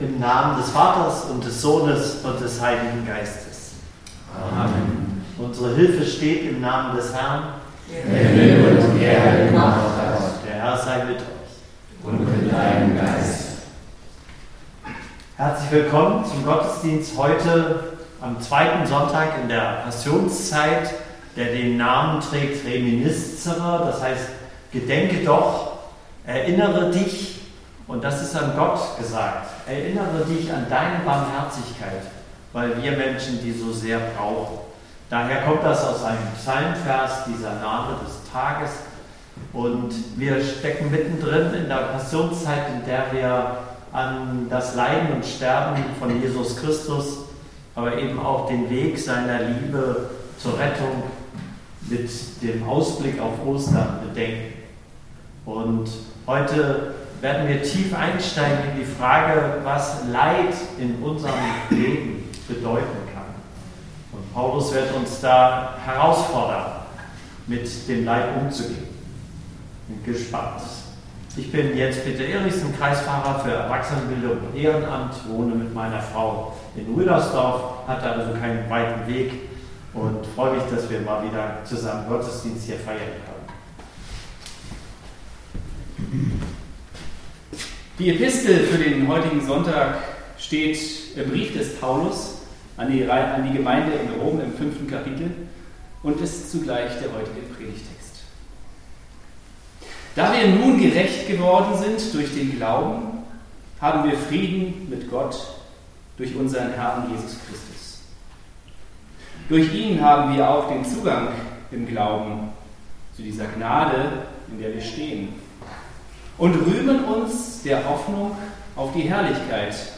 Predigt am Sonntag Reminiscere 1. März 2026